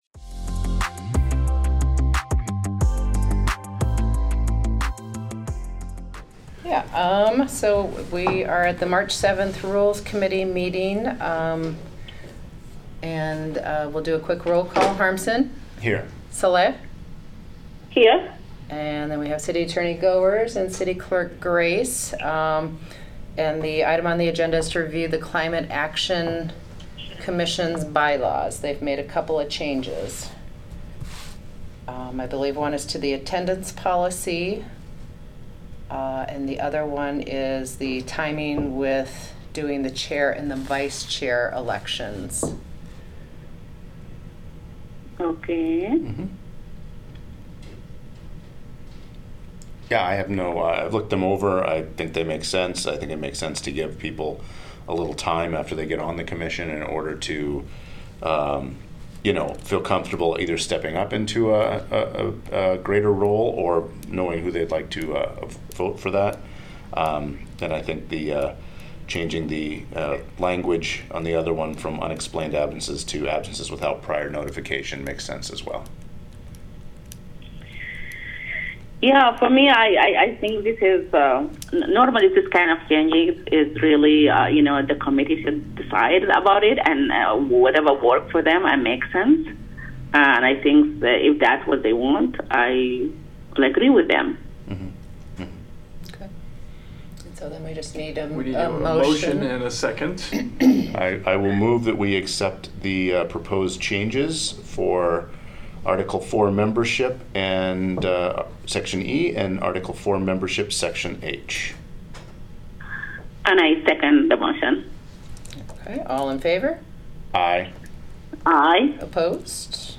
A meeting of the City of Iowa City's Council Rules Committee.